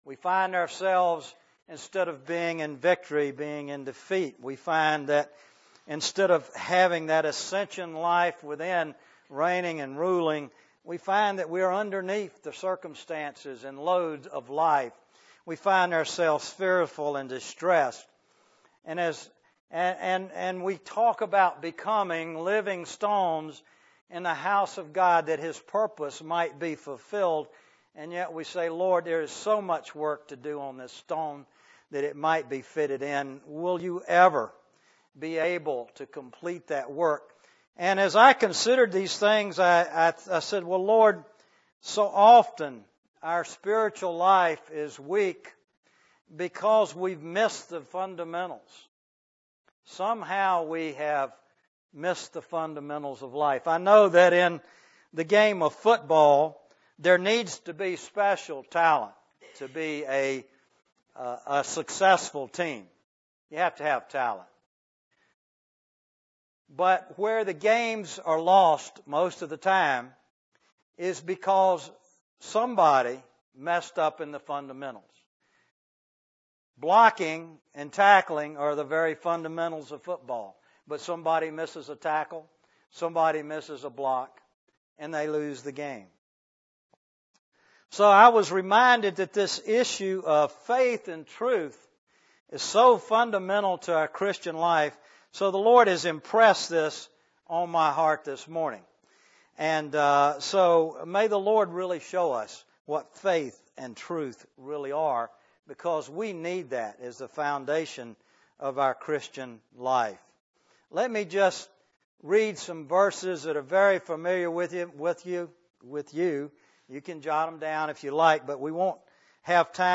A collection of Christ focused messages published by the Christian Testimony Ministry in Richmond, VA.
US Stream or download mp3 Summary The first couple of minutes of the message were lost.